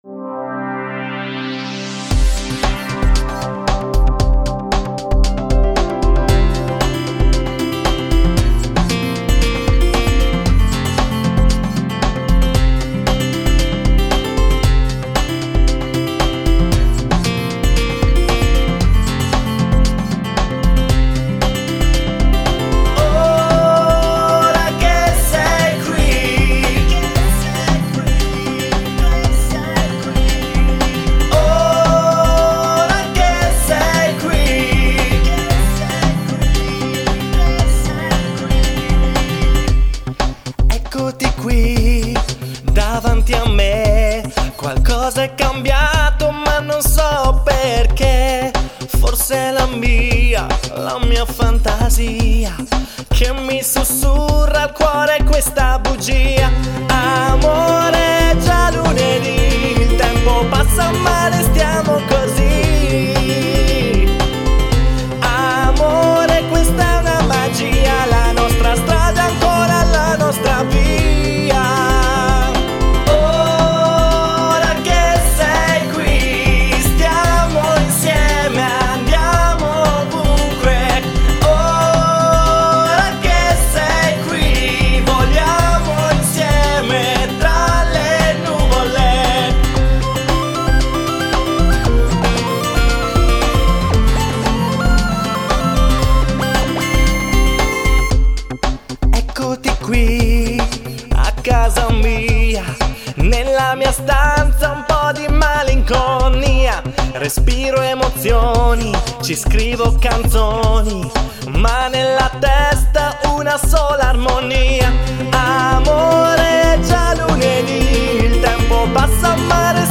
Come per “Ora che sei qui ” ho scelto un motivo orecchiabile e abbastanza estivo, sarà anche per la stagione!